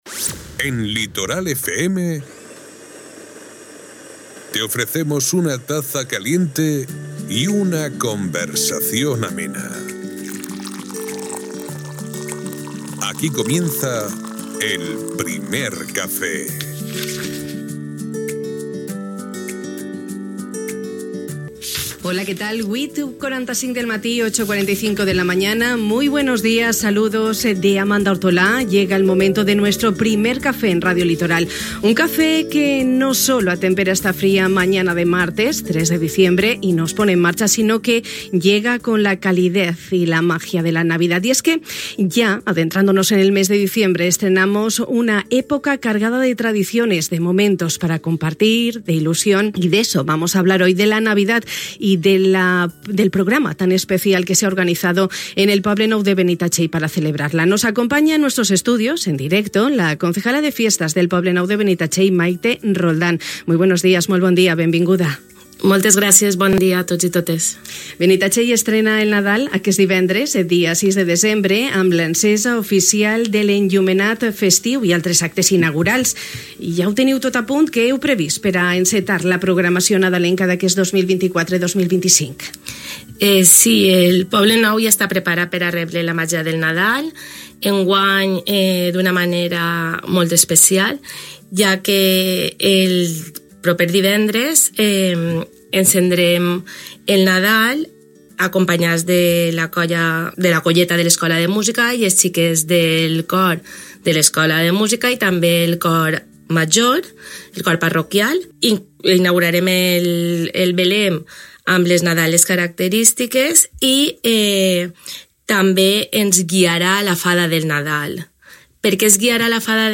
Amb la regidora de Festes de Benitatxell, Mayte Roldán, hem repassat els actes previstos que abasten del 6 de desembre al 6 de gener.